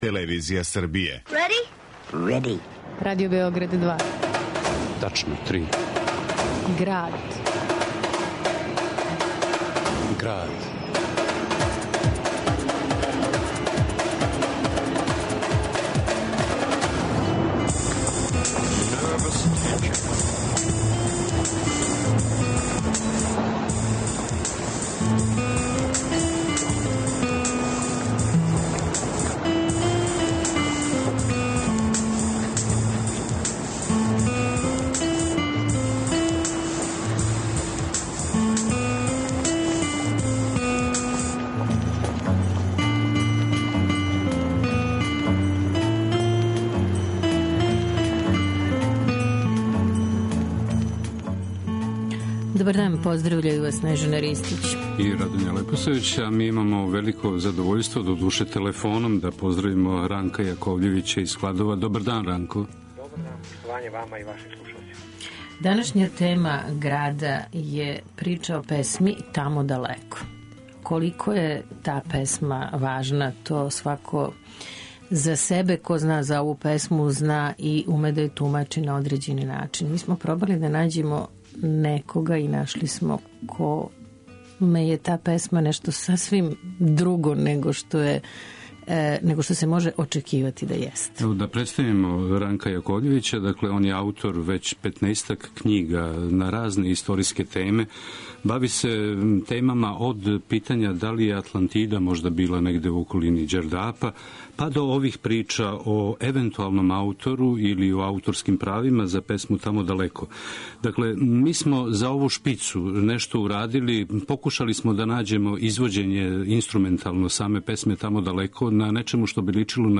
документарно-драмској емисији